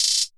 Closed Hats
BWB [WAVE 2] HAT ROLL (3).wav